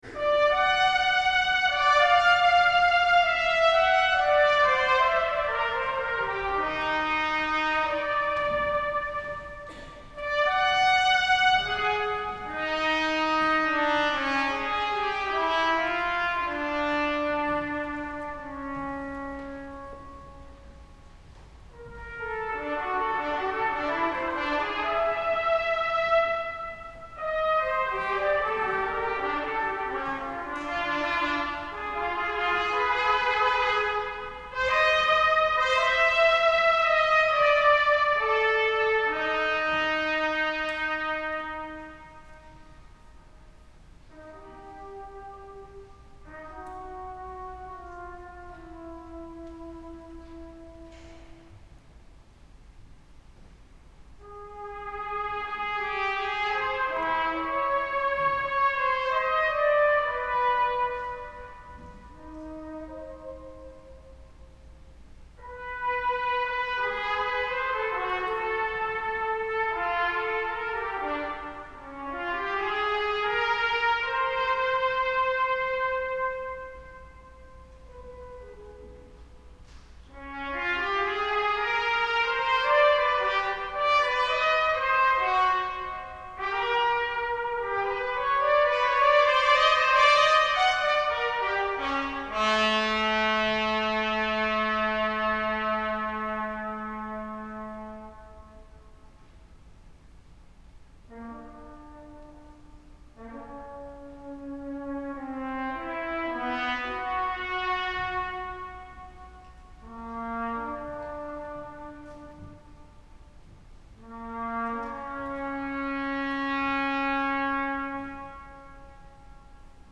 trumpet Duration